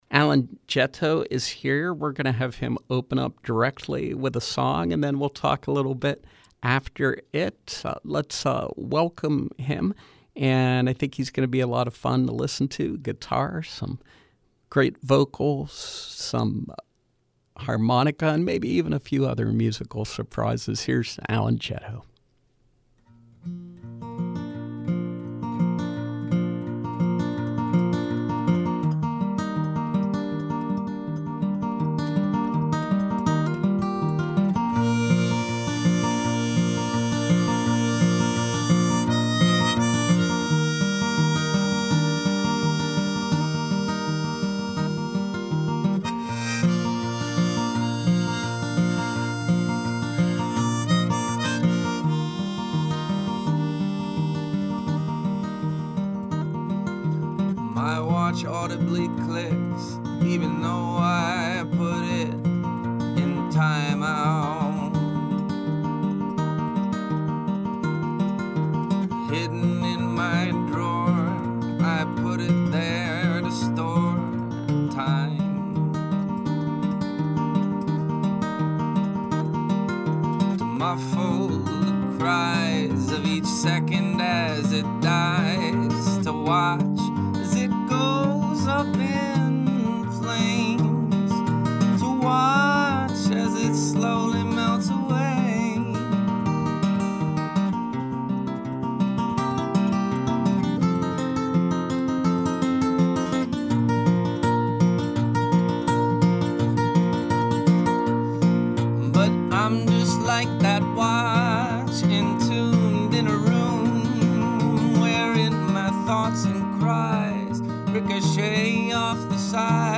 Live Music
Live music